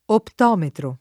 vai all'elenco alfabetico delle voci ingrandisci il carattere 100% rimpicciolisci il carattere stampa invia tramite posta elettronica codividi su Facebook optometro [ opt 0 metro ] (meno com. ottometro ) s. m. (med.)